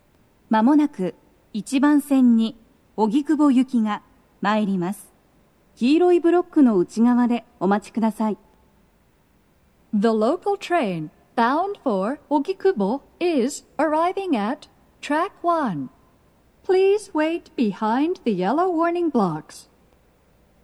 スピーカー種類 BOSE天井型
鳴動は、やや遅めです。
接近放送1
mshinotsuka1sekkinogikubo.mp3